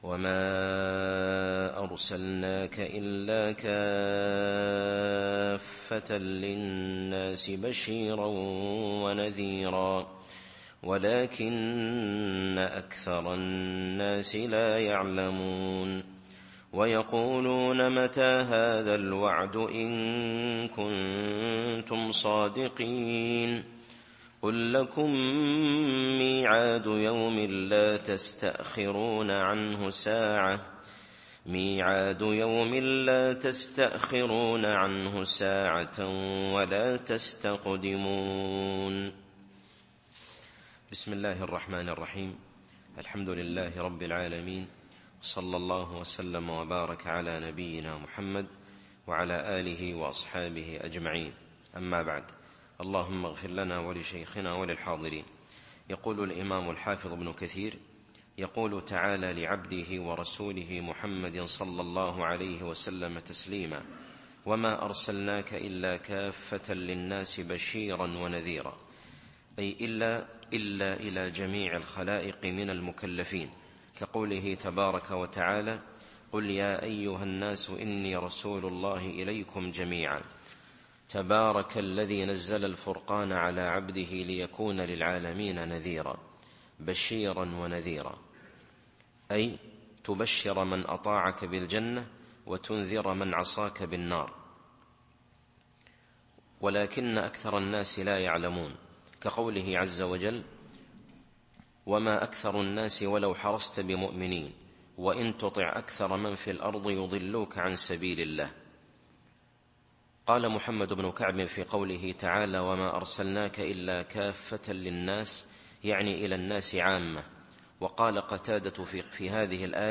التفسير الصوتي [سبأ / 28]